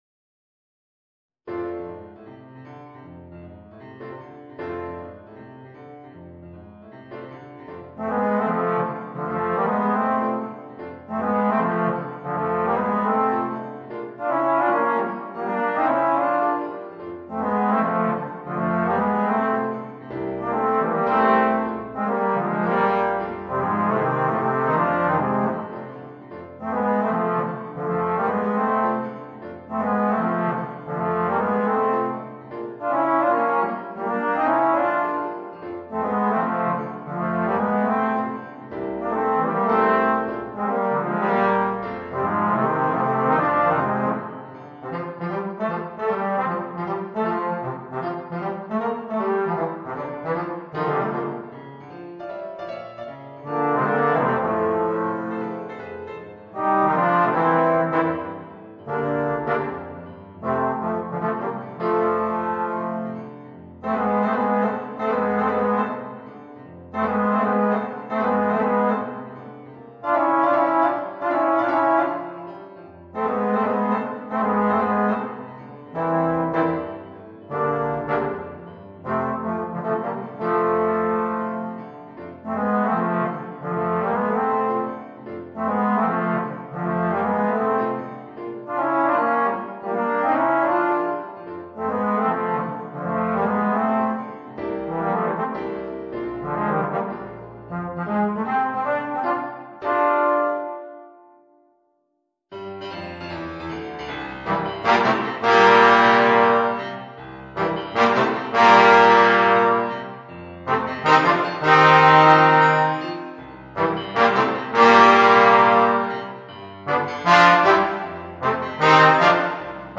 Voicing: 2 Trombones and Piano